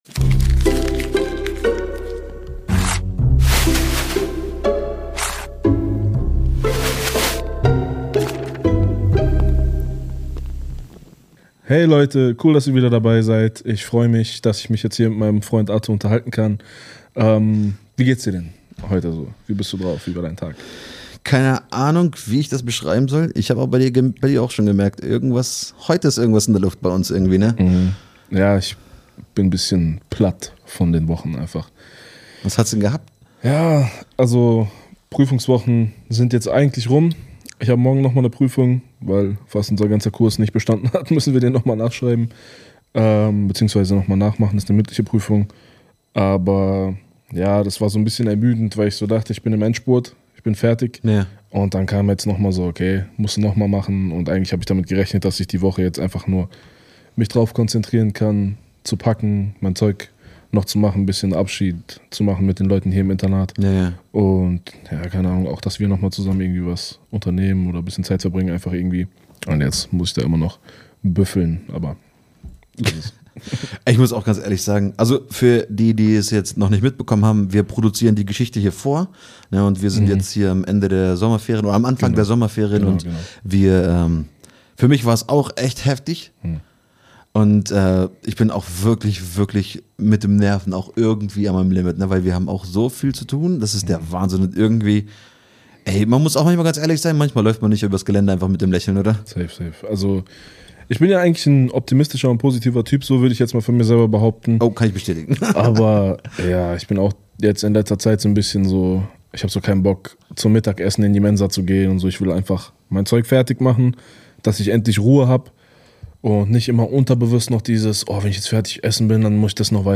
Zwei Kumpels, ehrliche Gespräche, echte Fragen – und eine Menge Inspiration fürs Leben.